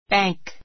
bank 1 A1 bǽŋk バ ン ク 名詞 ❶ 銀行 a bank clerk a bank clerk 銀行の事務員, 行員 a bank account a bank account 銀行口座 He put all the money in the bank.